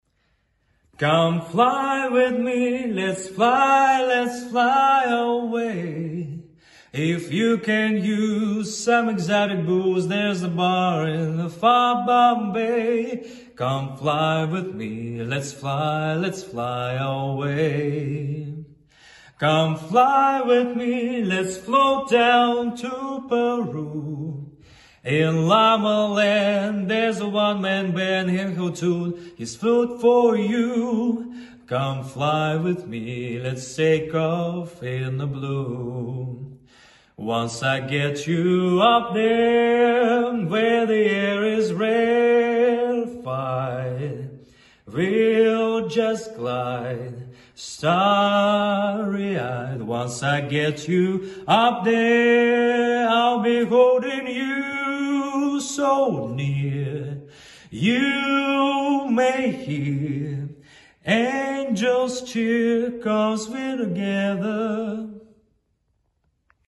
Мужской
Тенор Баритон